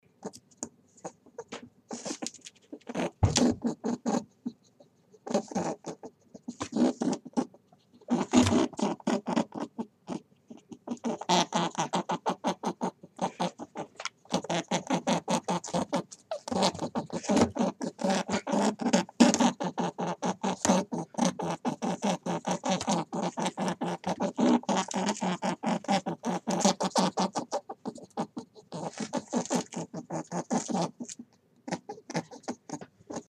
Звуки животных
Послушайте звуки кроликов